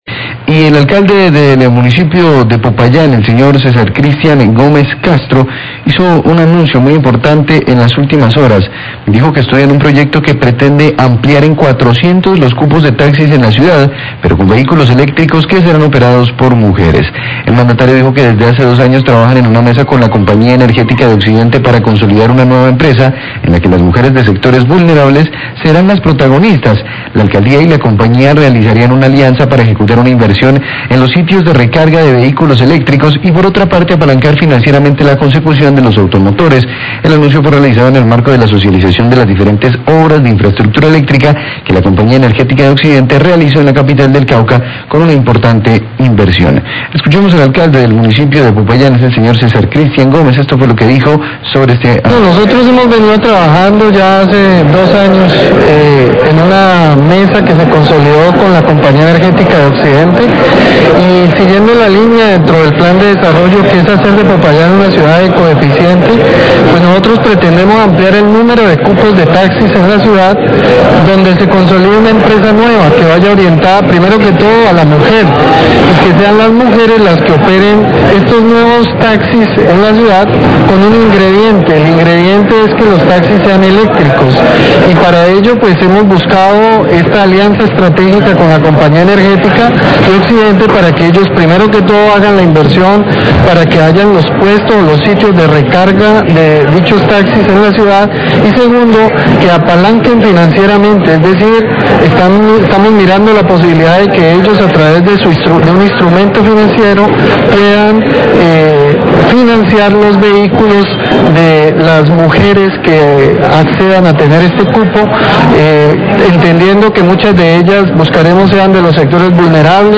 Radio
Declaraciones del Alcalde, Cesar Cristian Gómez.